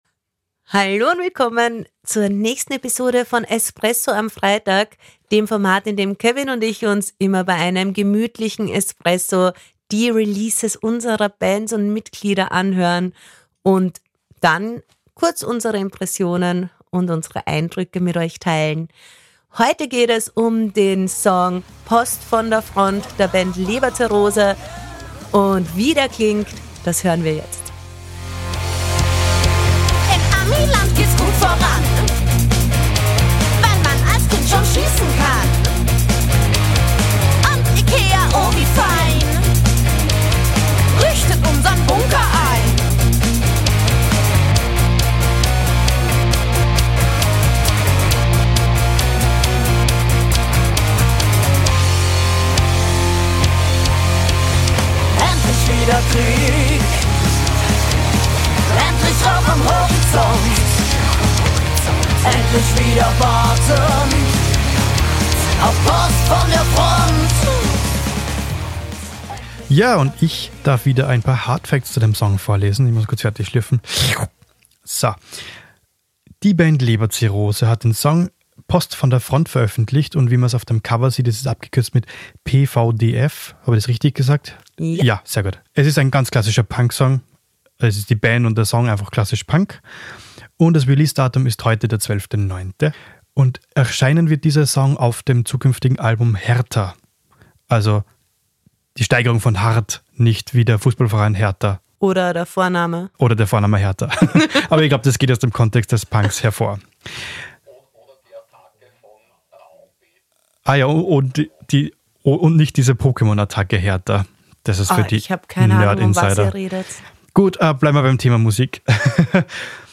Treibender Punk mit bissigem Text, der zwischen Sarkasmus und bitterer Realität schwankt.